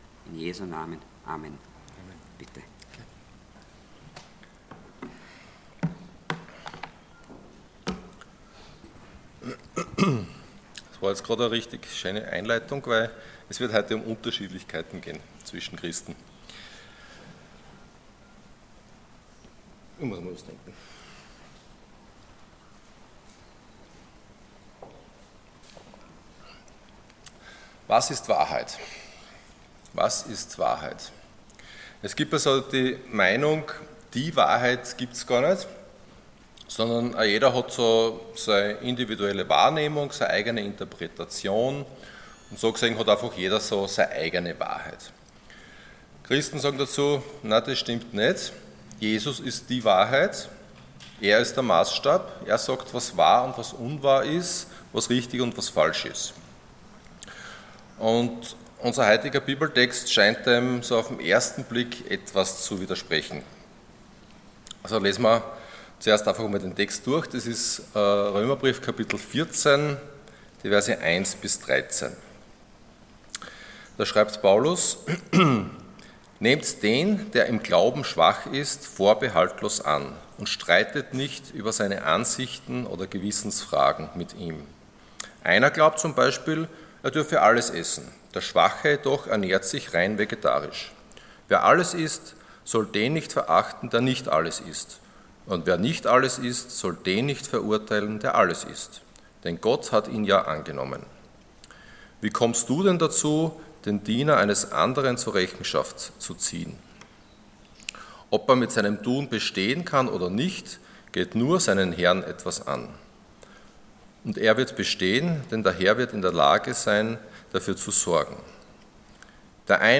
Prediger
Dienstart: Sonntag Morgen